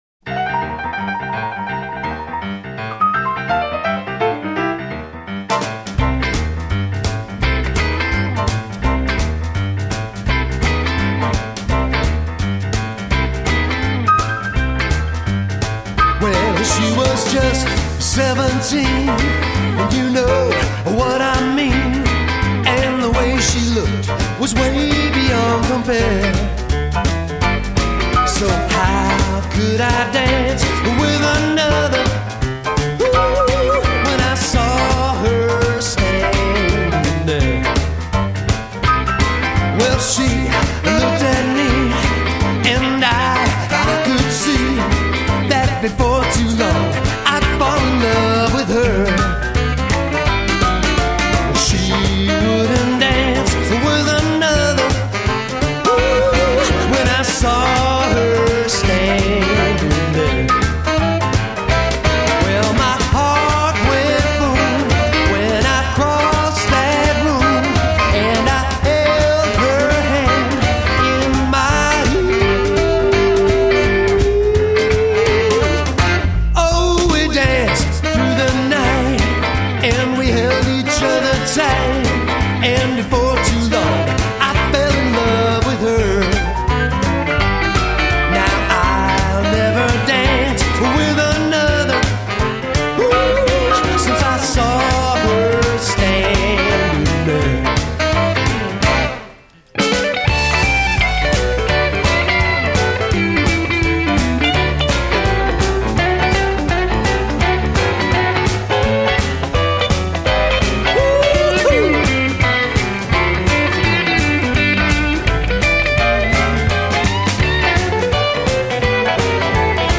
05 Jive